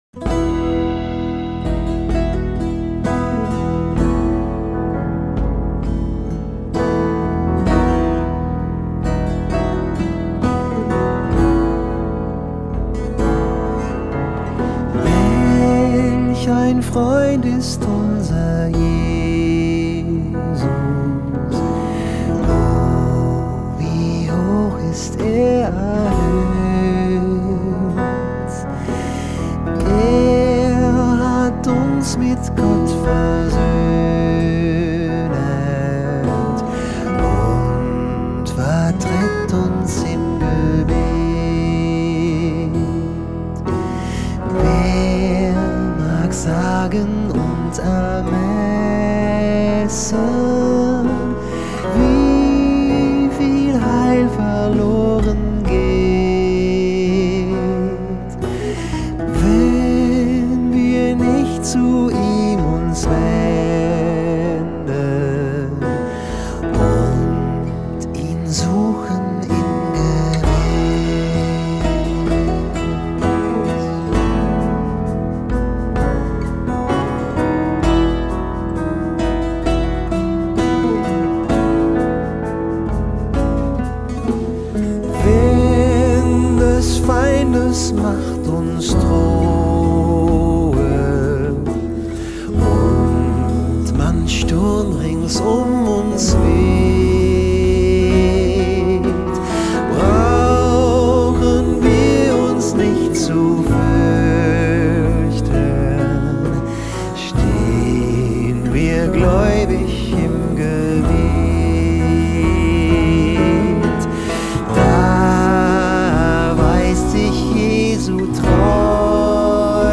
55 просмотров 80 прослушиваний 0 скачиваний BPM: 70